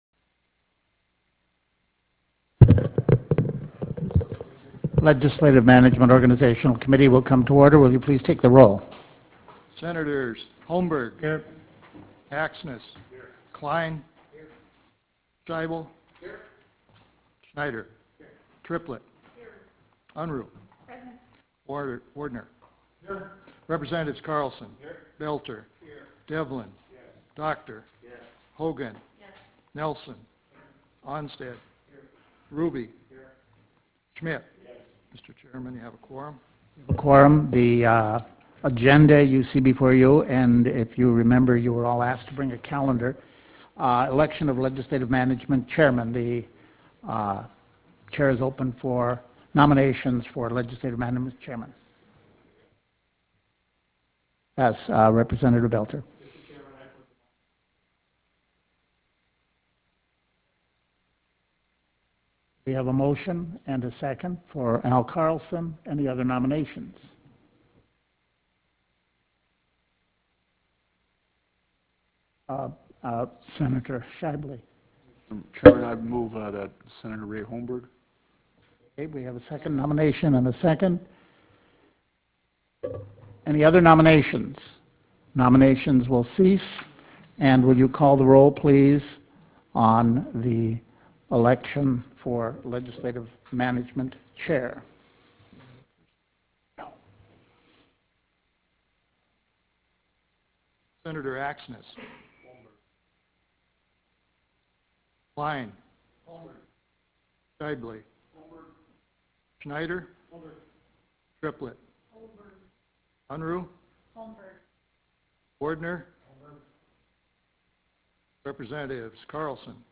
Meeting is scheduled immediately following the 3:00 p.m. floor session.
Prairie Room State Capitol Bismarck, ND United States